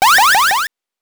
8 bits Elements
powerup_1.wav